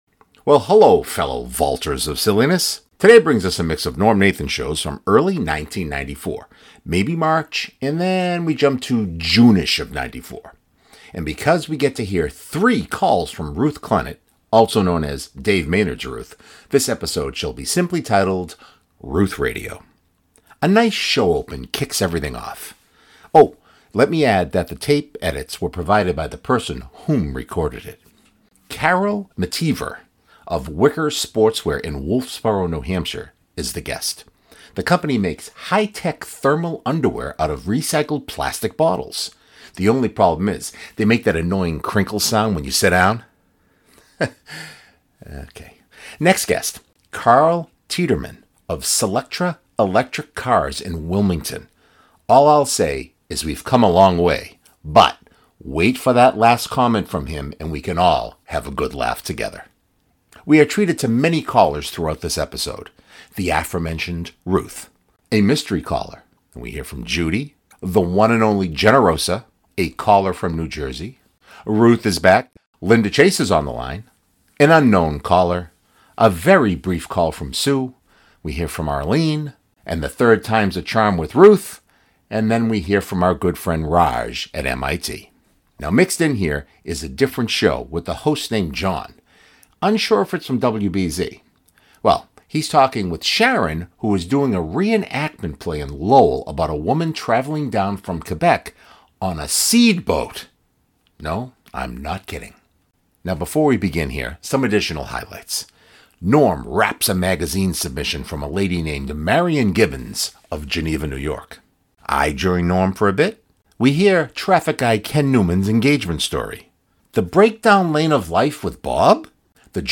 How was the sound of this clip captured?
Oh, I will add that the tapes edits were provided by the person whom recorded it.